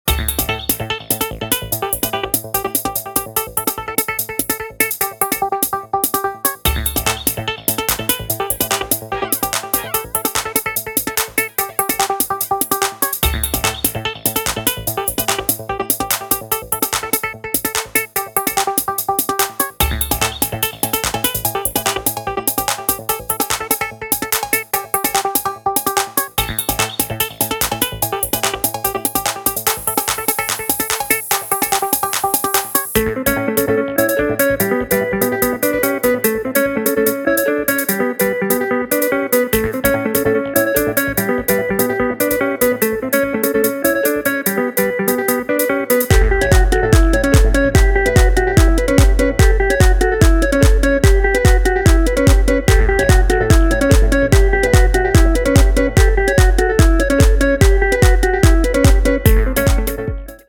Vibrant House music
House Acid